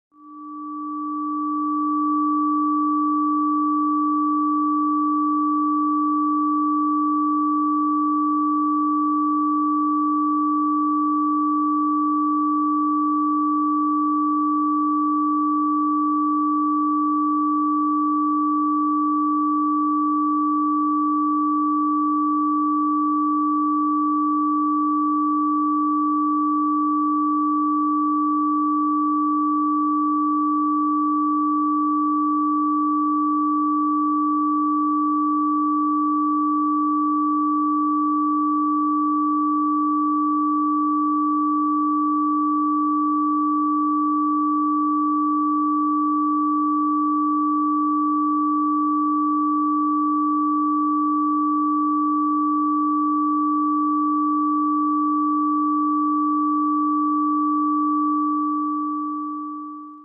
Adéntrate en la profunda resonancia de la frecuencia binaural de 852 Hz, una aliada poderosa para la meditación y la relajación. Experimenta la mejora de la concentración, la elevación de la claridad mental y el fortalecimiento de la intuición, al tiempo que se cree que armoniza el chakra del tercer ojo.